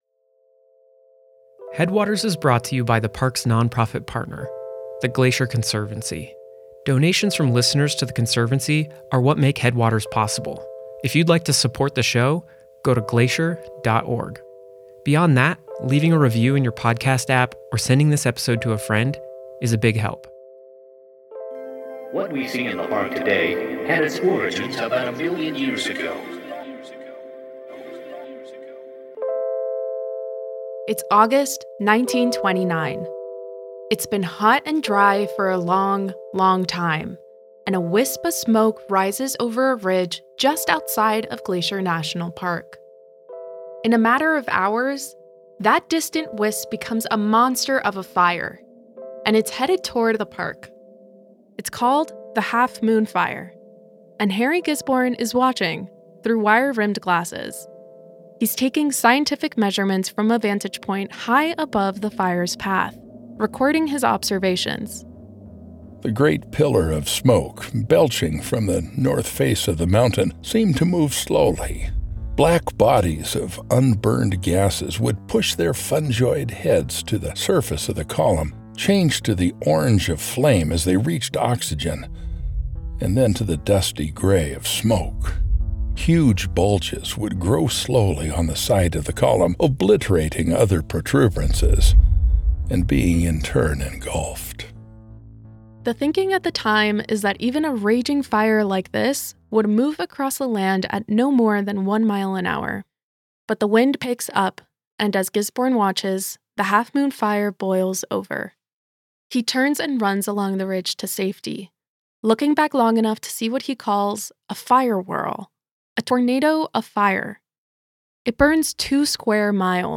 [moody chords start]
[moody chords continue] It's August 1929.
Harry Gisborne [Voice Actor]: [moody bass fades in] The great pillar of smoke belching from the north face of the mountain seem to move slowly.